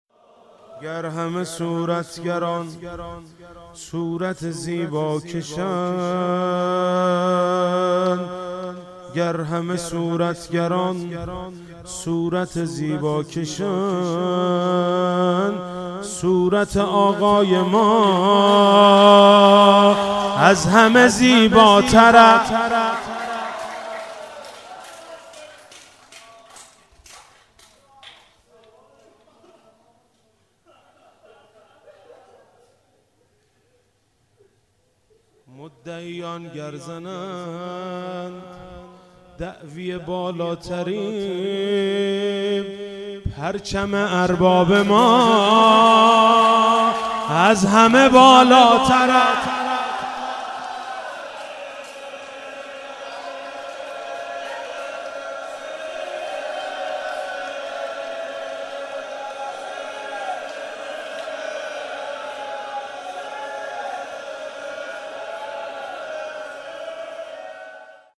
ماه رمضان
شعرخوانی مداحی